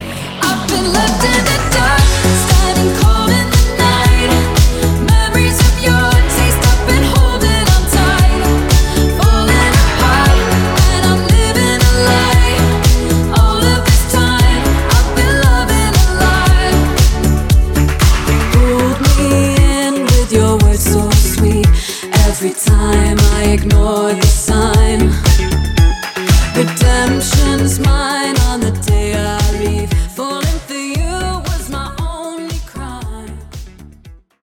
• Качество: 320, Stereo
громкие
женский вокал
диско
nu disco